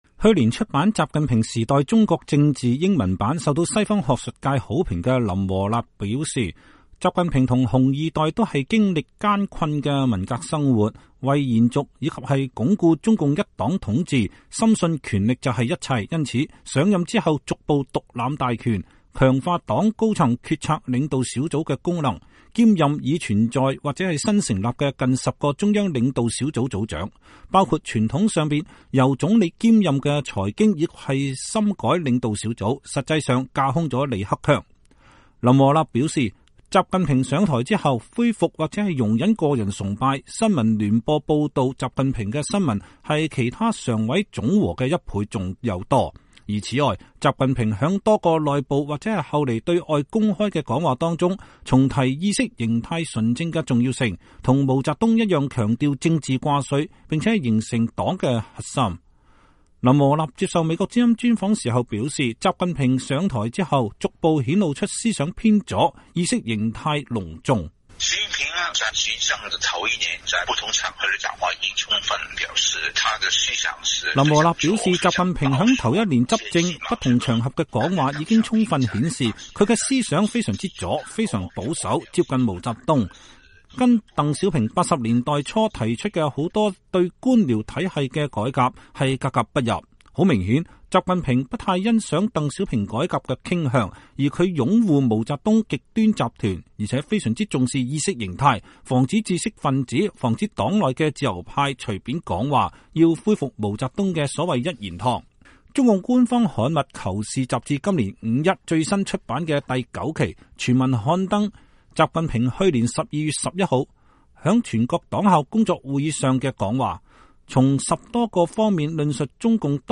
林和立接受美國之音專訪時表示，習近平上台之後，逐步顯露出思想偏左，意識形態濃重。